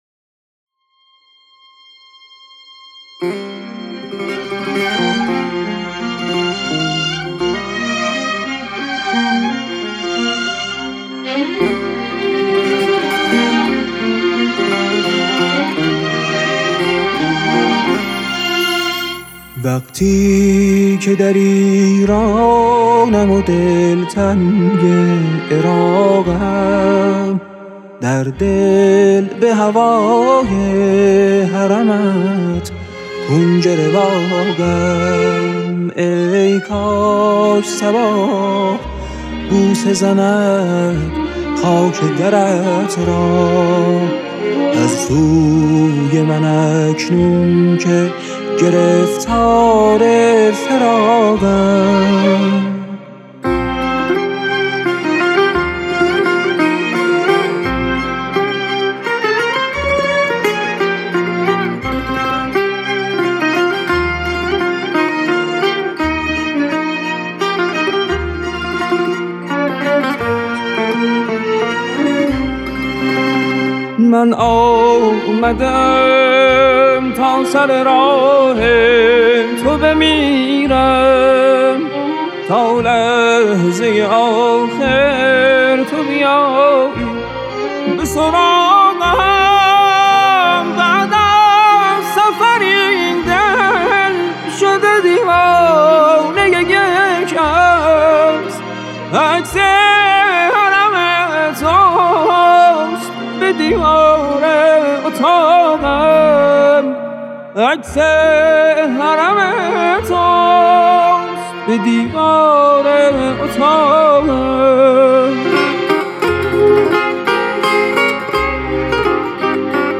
تصنیف
غزل آیینی
ارکستر زهی
این اثر موسیقایی در آواز دشتی و ابوعطای دستگاه شور ساخته شده